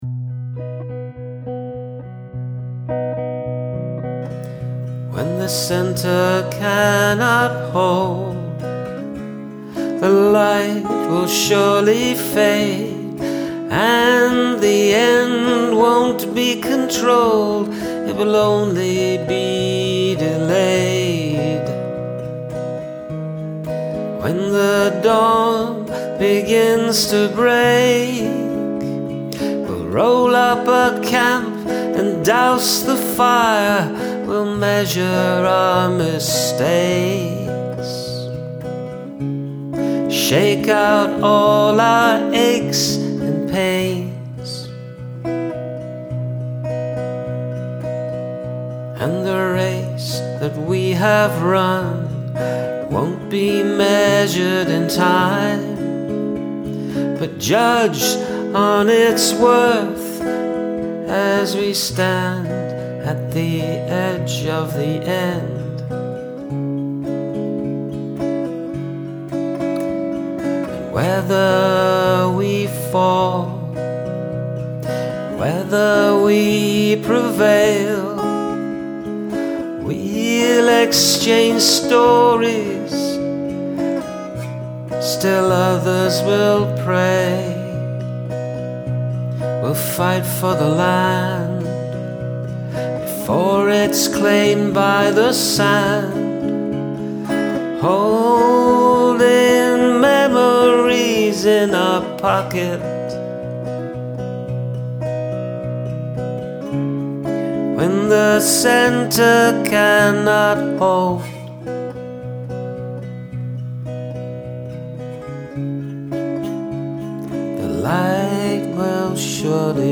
Beautiful melancholic feel to this.
I love the tone of your guitar and voice.
Beautiful melody and use of the 8 words :)